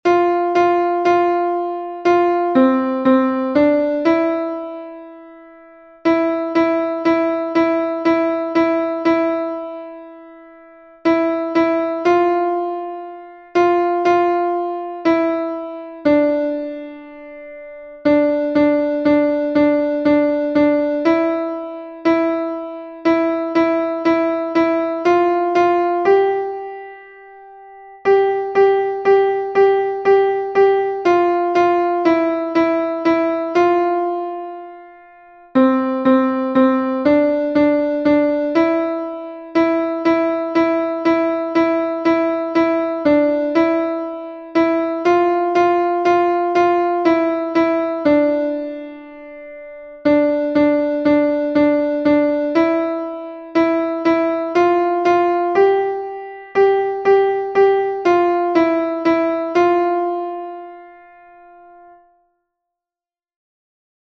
alti-mp3 1er février 2021